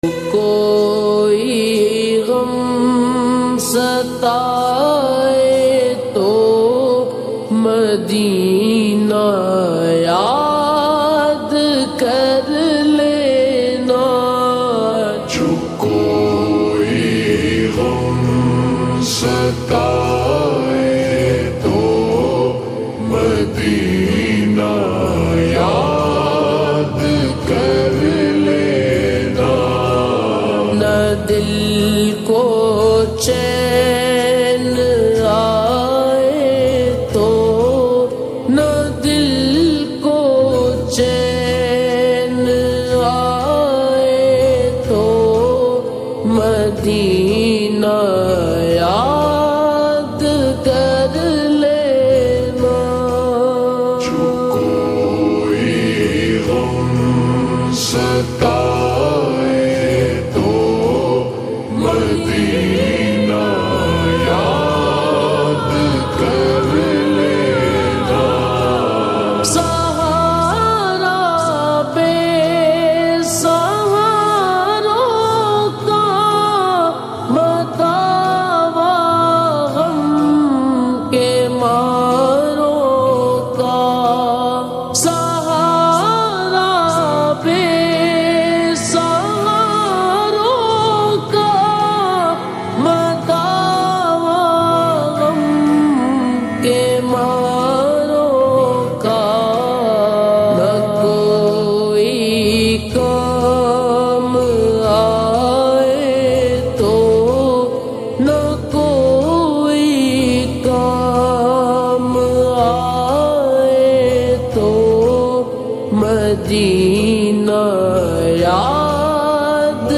Slowed Reverb Naat